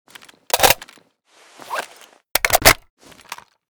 famas_reload.ogg